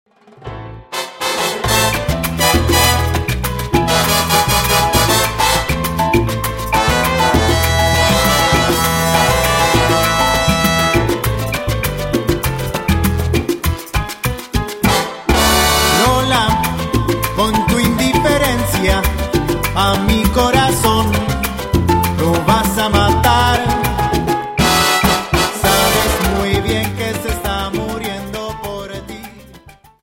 Dance: Salsa 50